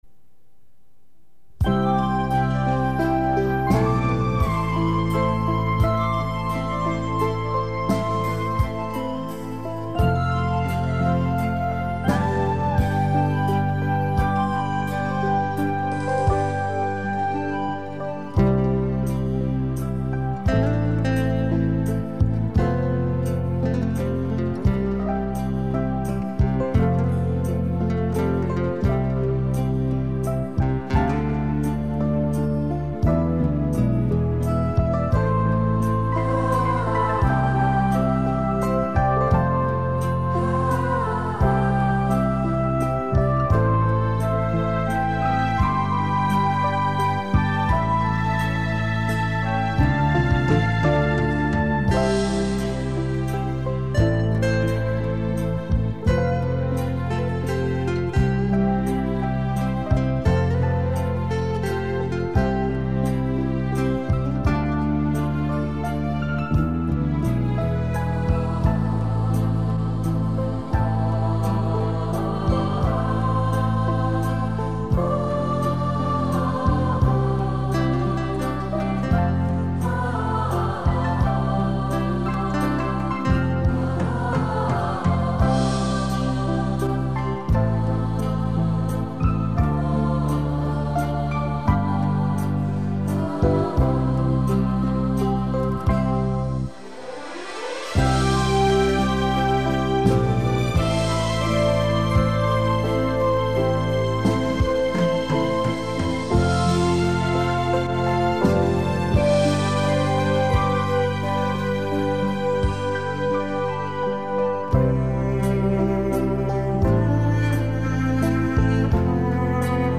慢三) (低音质128K.mp3